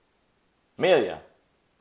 Amelia wakewords from 8 speakers of varying ages, genders and accents.